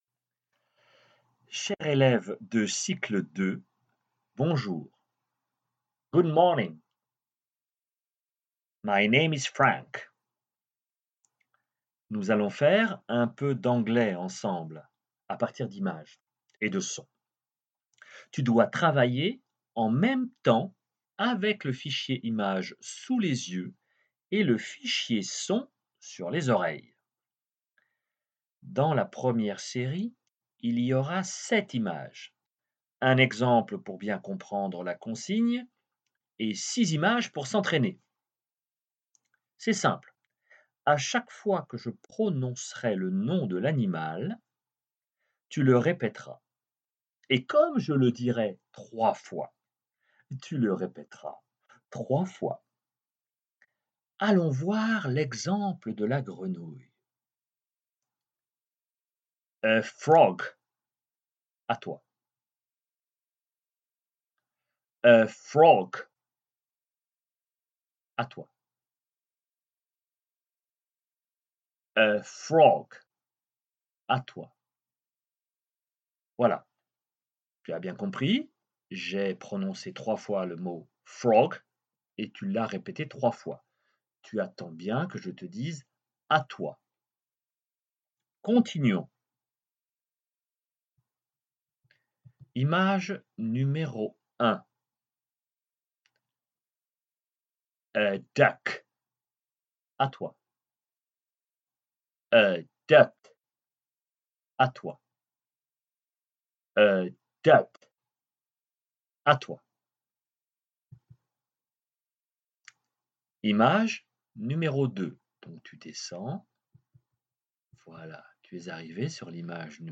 Voici un fichier son indissociable de son fichier image pour s'entraîner à prononcer des mots en anglais, pour les CP et pour les CE1.